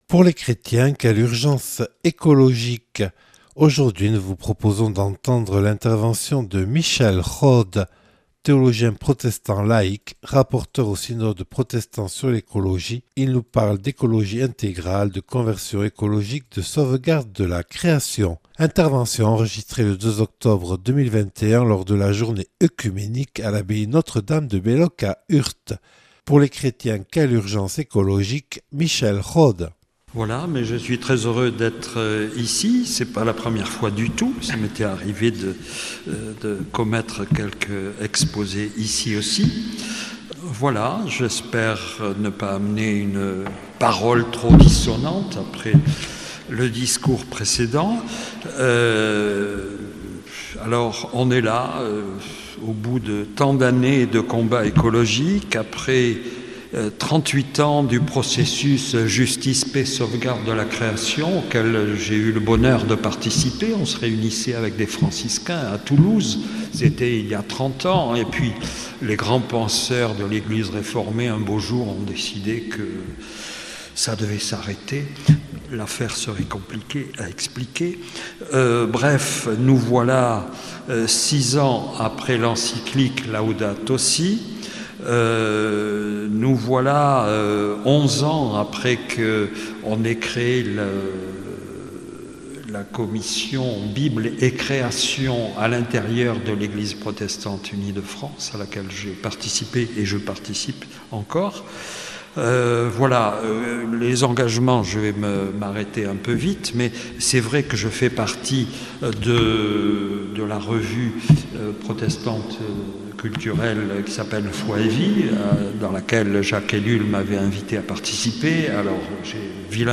Accueil \ Emissions \ Magazines \ Religion \ « Pour les chrétiens, quelle urgence écologique ?